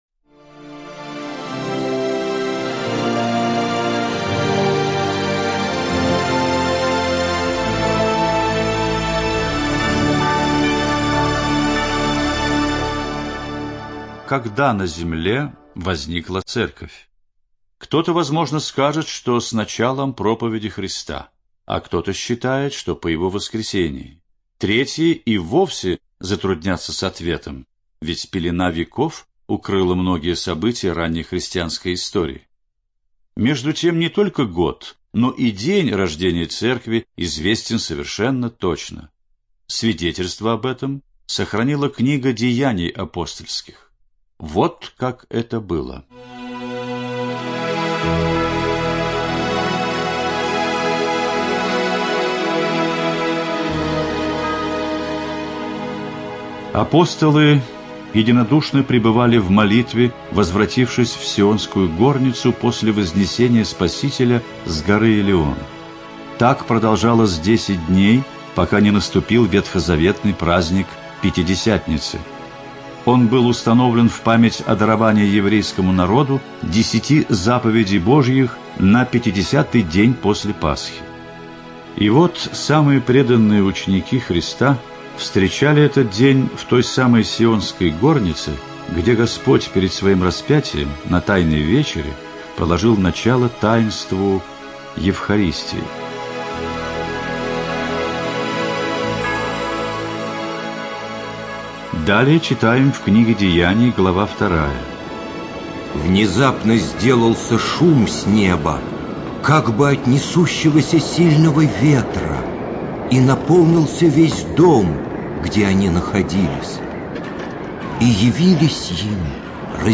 ЖанрХристианство, Радиопрограммы